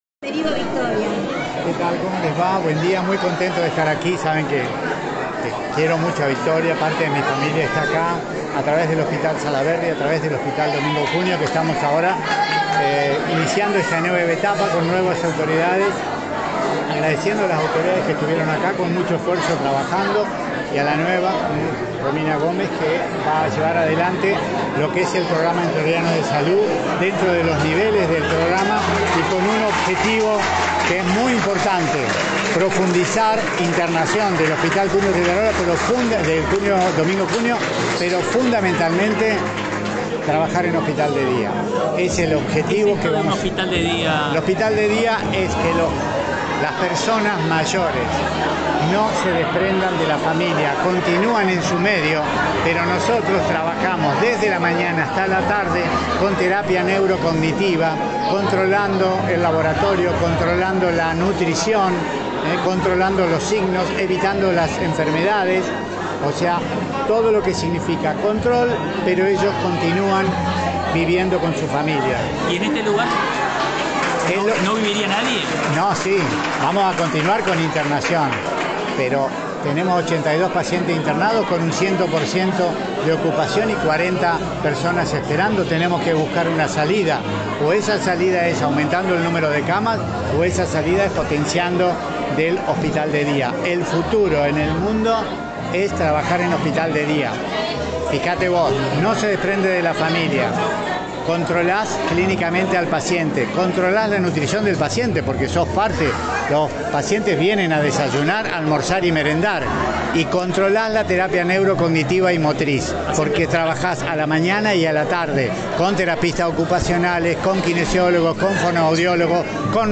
El Ministro de Salud, Guillermo Grieve sorprendió este miércoles en rueda de prensa al señalar su “obsesión” por la instalación de una Unidad de Cuidados Críticos en Victoria.
Ministro de Salud – Guillermo Grieve